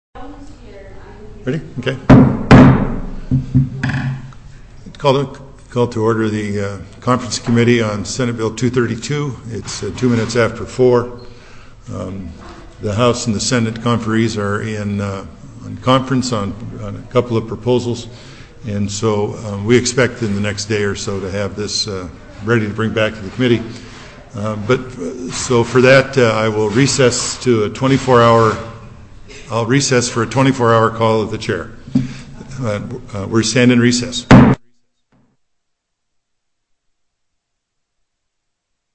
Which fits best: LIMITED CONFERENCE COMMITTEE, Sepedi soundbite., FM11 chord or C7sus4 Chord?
LIMITED CONFERENCE COMMITTEE